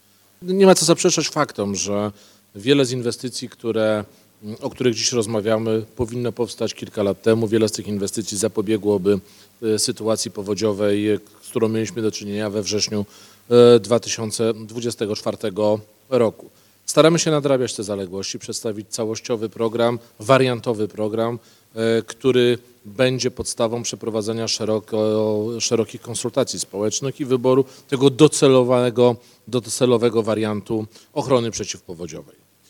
–  To program, który nadrabia wieloletnie zaniedbania w tym zakresie [ochrony przed powodzią [przyp. red.] – mówił we Wrocławiu minister Marcin Kierwiński.